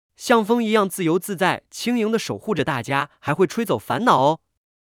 wind.mp3